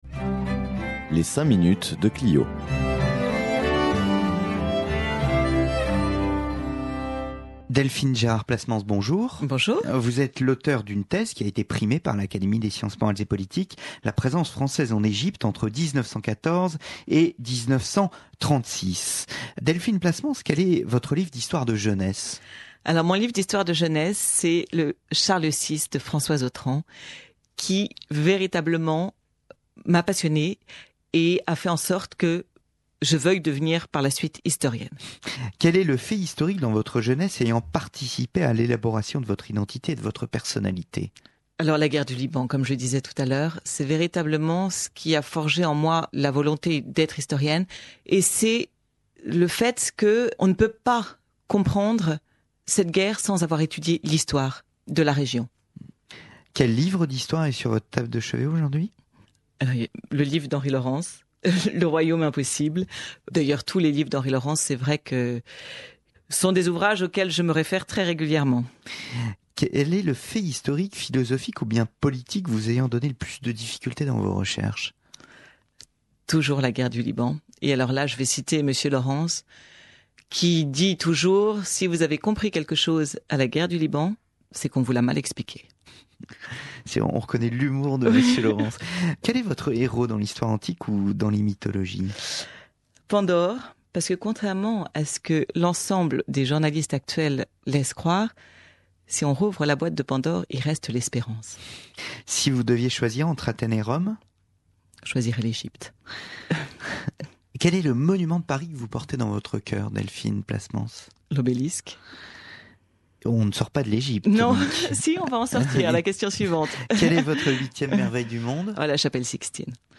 Le sympathique questionnaire historique